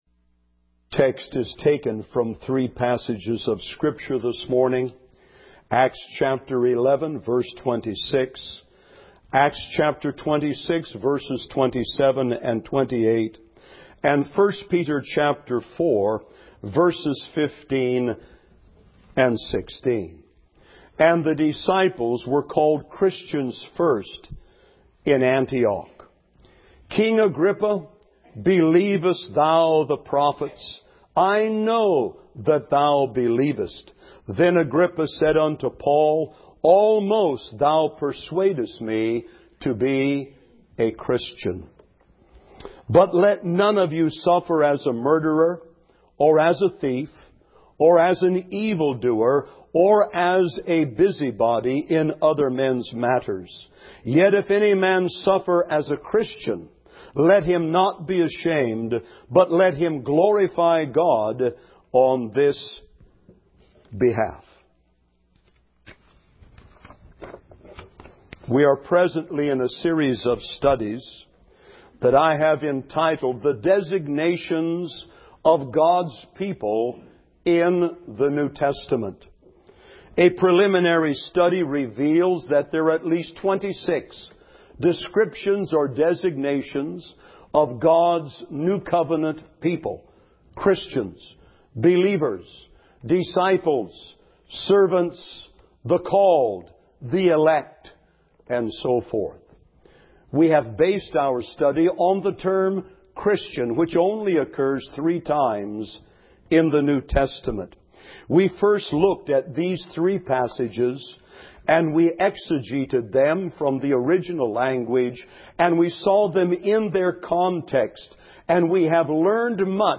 Sermons - Sovereign Grace Baptist Church of Silicon Valley